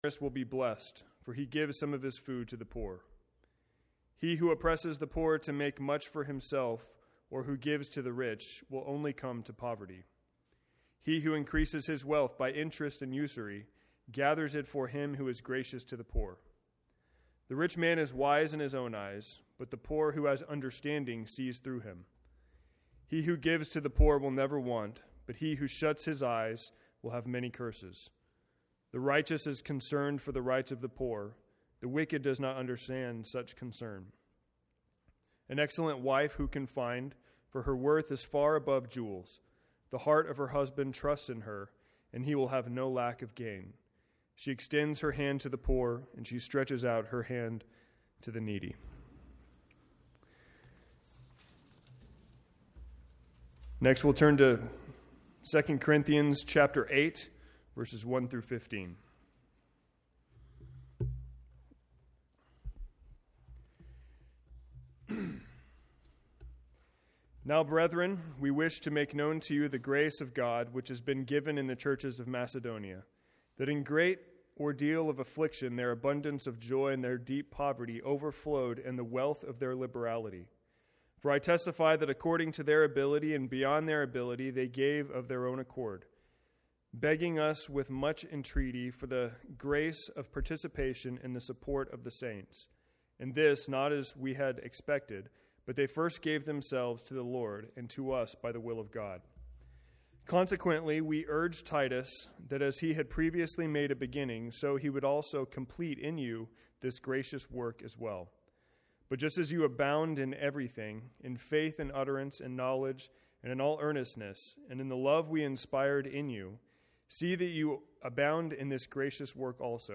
Explore our sermon library below to play, download, and share messages from McKinney Bible Church.